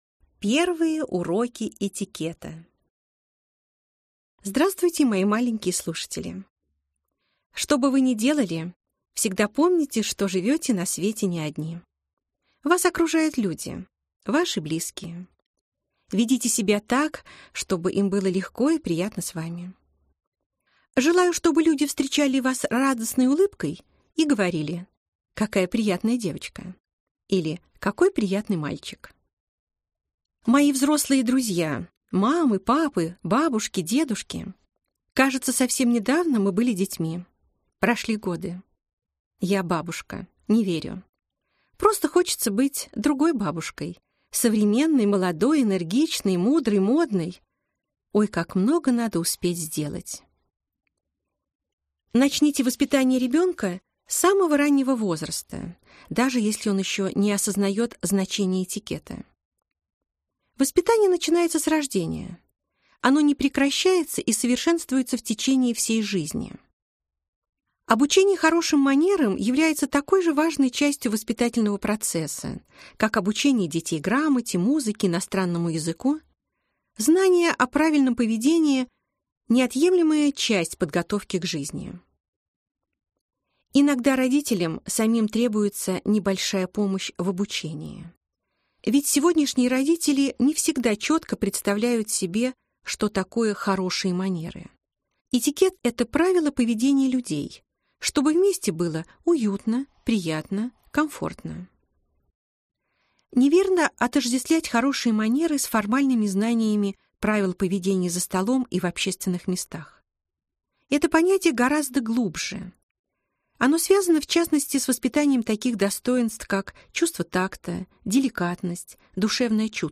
Аудиокнига Аудиокурс. Как вырастить ребенка вежливым и воспитанным | Библиотека аудиокниг